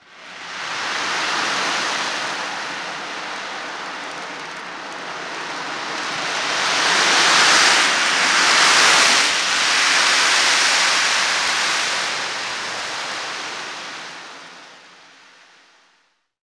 Index of /90_sSampleCDs/NorthStar - Global Instruments VOL-2/PRC_JungleSounds/PRC_JungleSounds